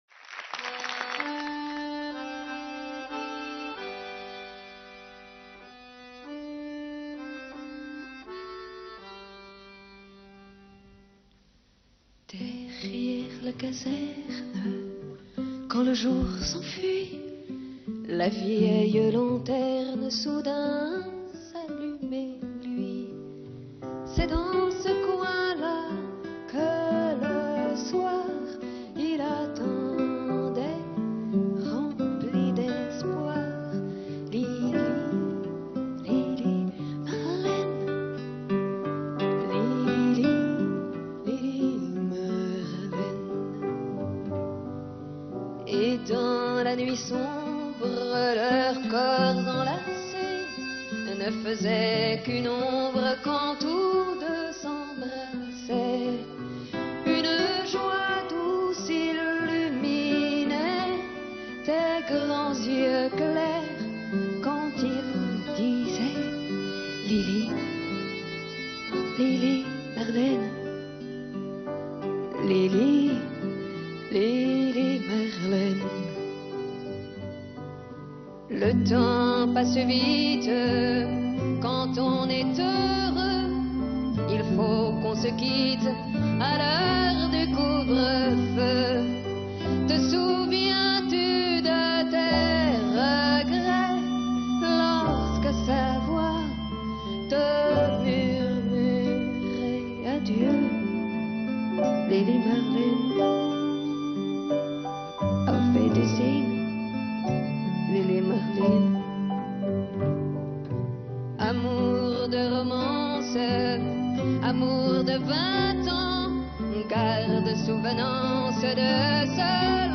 Chœur d’hommes fondé en 1860